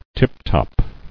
[tip·top]